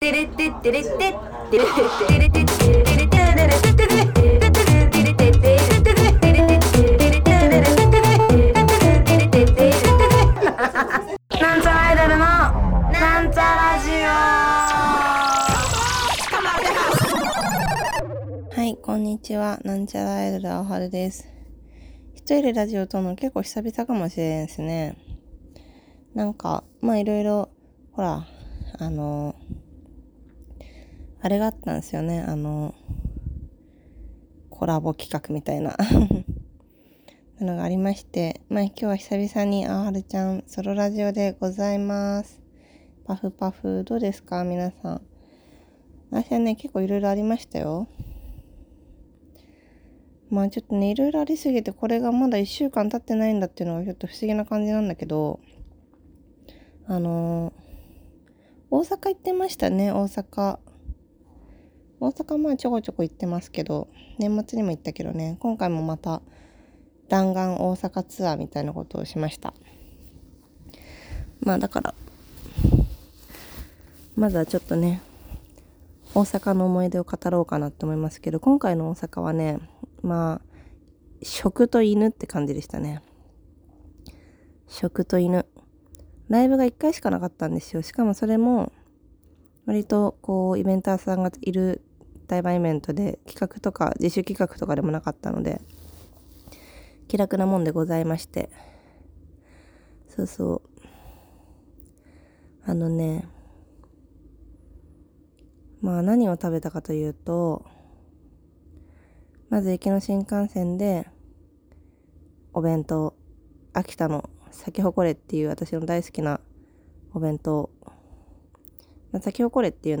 久しぶりのソロラジオです